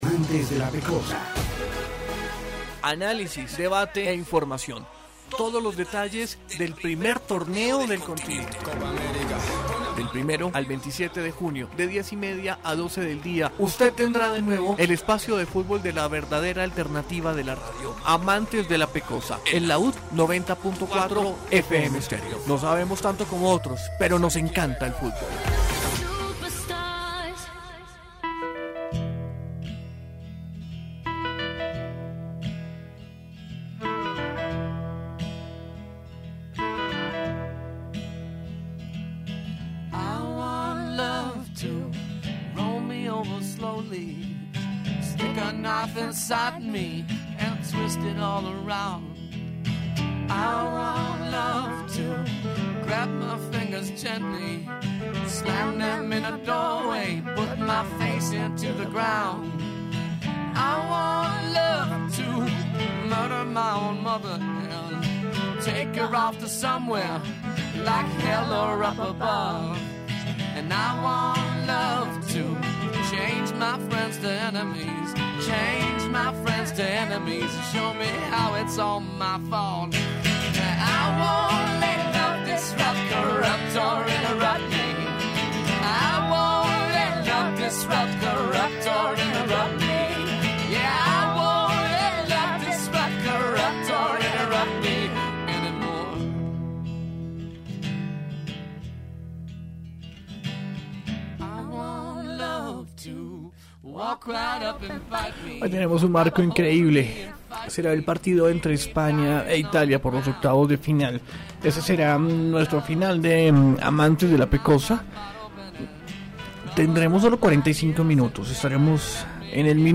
In this interview, journalist Esteban Jaramillo provides a vehement and critical analysis of the Colombian national team's participation in the Copa América Centenario. Jaramillo rejects complacency with third place, arguing that the team has the ability and the obligation to win.